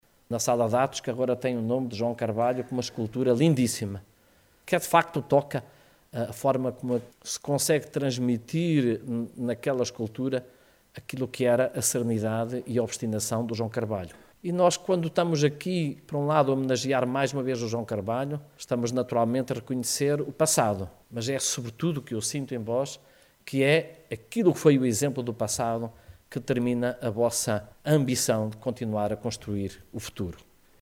A sessão solene contou com a presença do secretario de estado do Ensino Superior, João Sobrinho Teixeira, que veio para felicitar o caminho do IPCA ao longo destes 25 anos:
secretario-estado-ipca.mp3